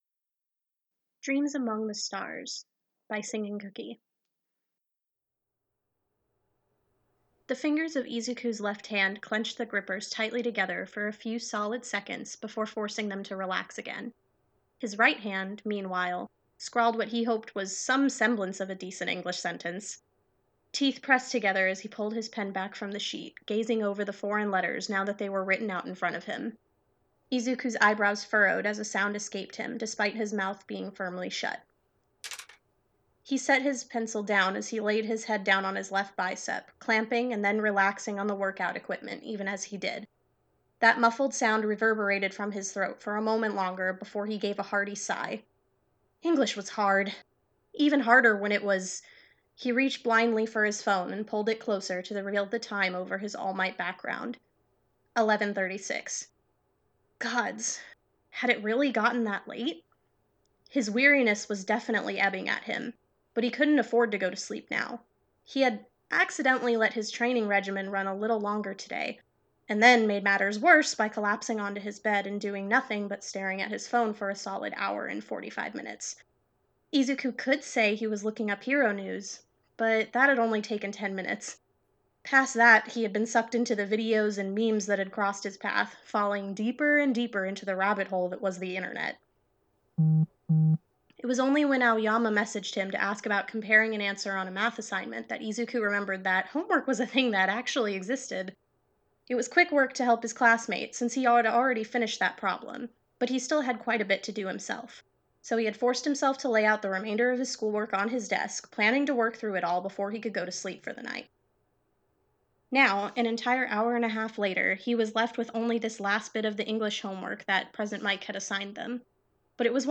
Dreams Among the Stars | Podfic
Voice of Izuku Midoriya
" glassbottle on wooden table fx.wav "
" Elevator Hum 3.wav "